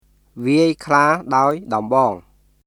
[ヴィアイ・クラー・ダオイ・ドンボーン　viˑəi kʰlaː　daoi dɔmbɔːŋ]